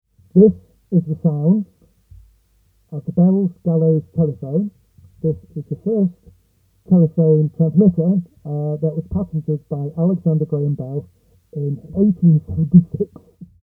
We connected the output from the transformer to a balanced microphone input on a UA Volt 2 interface, and spoke loudly into the diapghragm.
The sound is somewhat muffled and very peaky, but the voices are just about intelligible.
MoMIcs_Bells_Gallow_FirstNewSkin_0.2mmGap.mp3